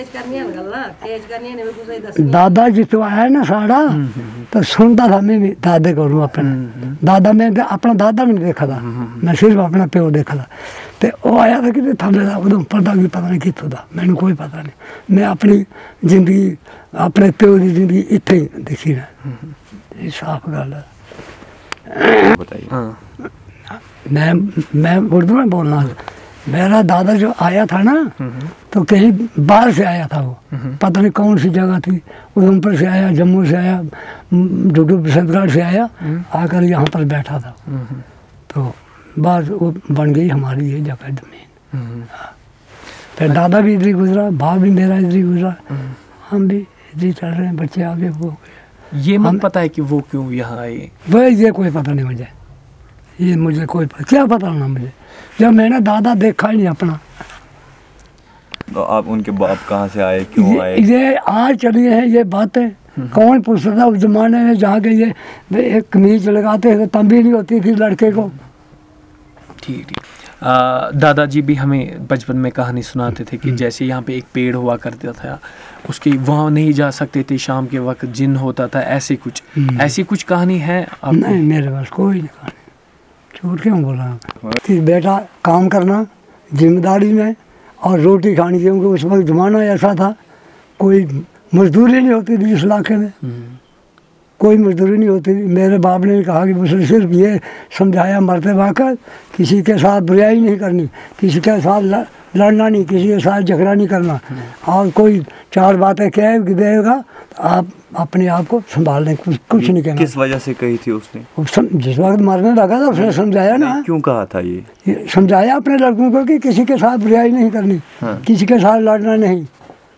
Personal narrative on the details and origin of the consultant